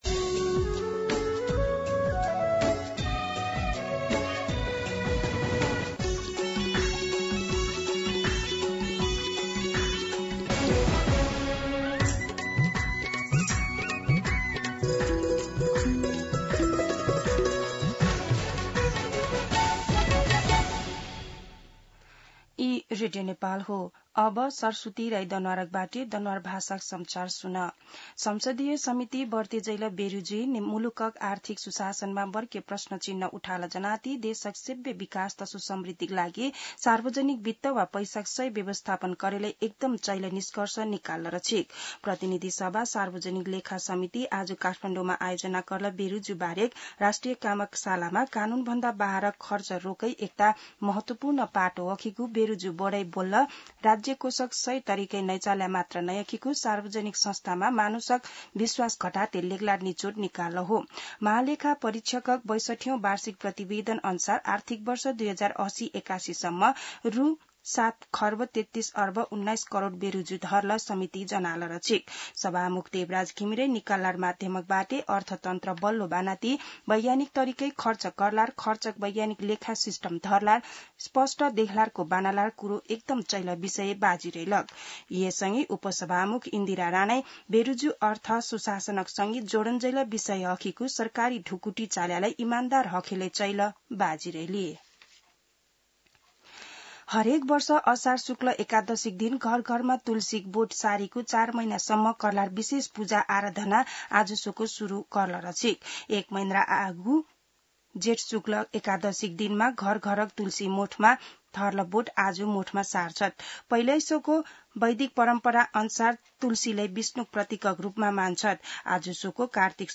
दनुवार भाषामा समाचार : २२ असार , २०८२
Danuwar-News-22.mp3